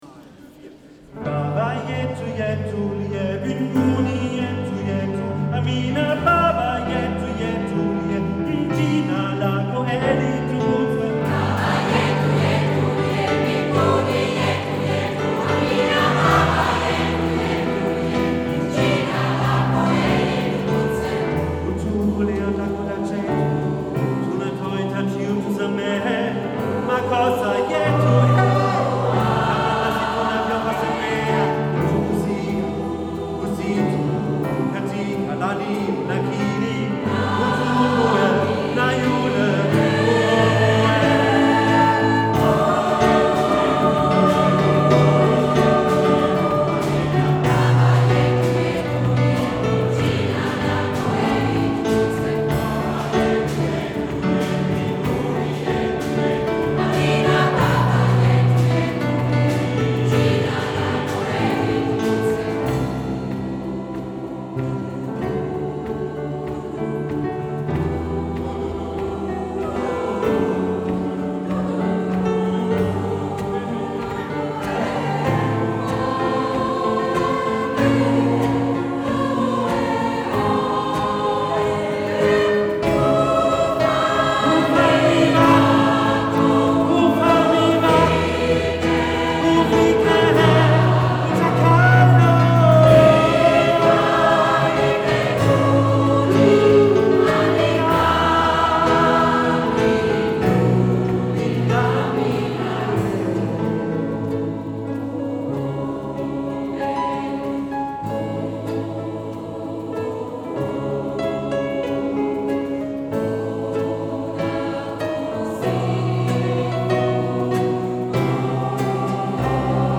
Komm in unseren gemischten Chor schnuppern und finde selbst heraus, ob es dir bei «the waves» in Goldach gefällt.
Oder höre die Kostproben von unserem Konzert in St. Gallen am 9. November 2024 (zusammen mit „Queerbeat Gaiserwald“).